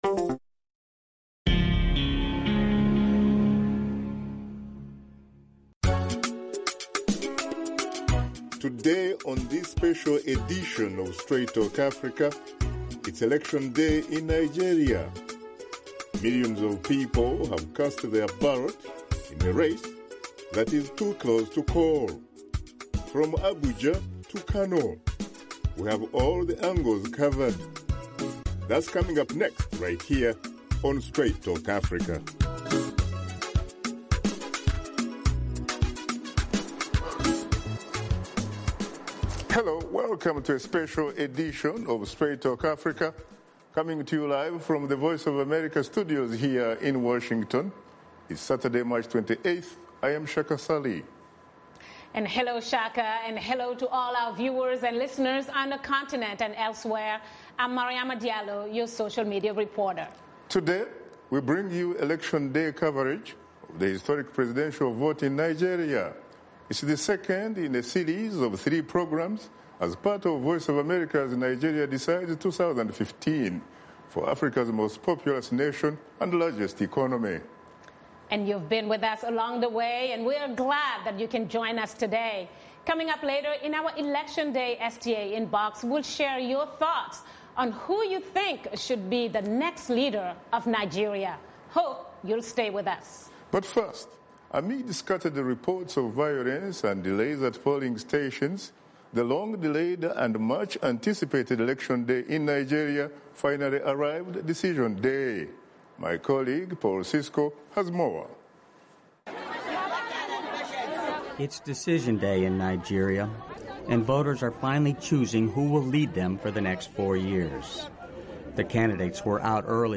Shaka Ssali and his guests take part of Special edition of "Straight Talk Africa" as part of VOA's “Nigeria Decides 2015” Election Day coverage.